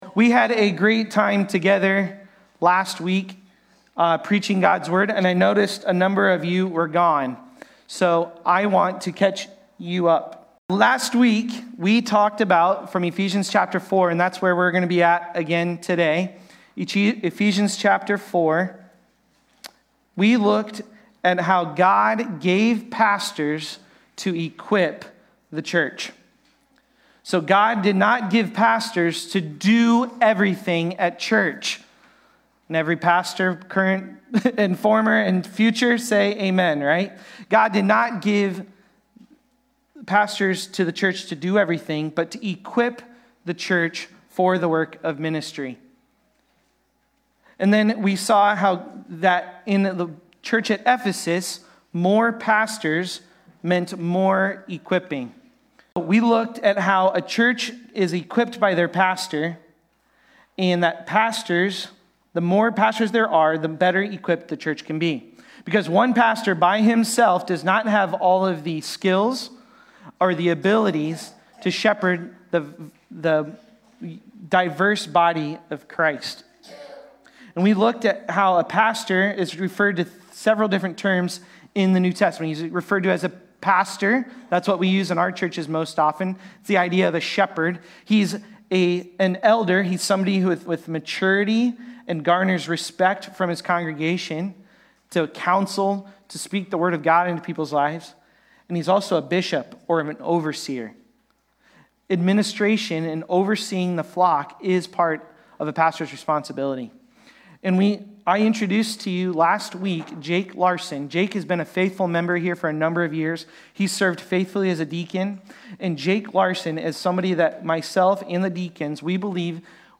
Growing-Together-Eph-4.13-16-Sermon-Audio.mp3